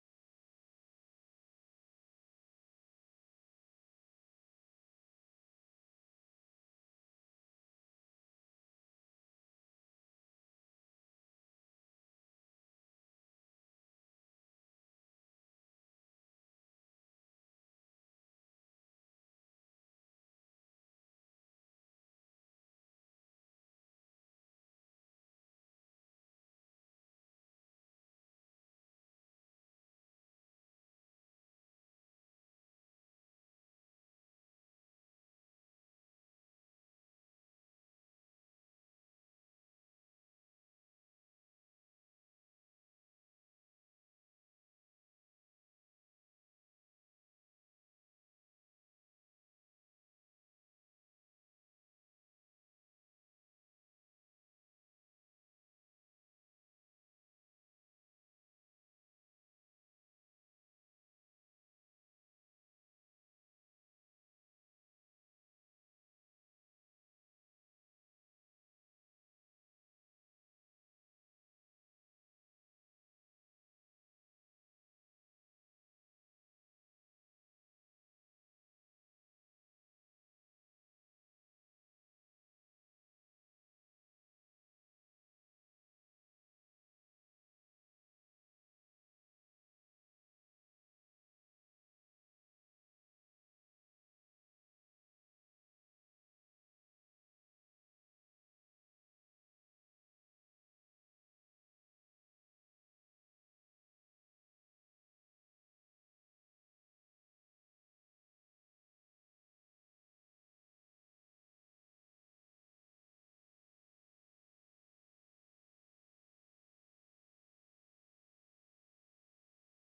الان-مباشرة-من-النجف-الاشرف-ليلة-٤-محرم-١٤٤٦هـ-موكب-النجف-الاشرف.mp3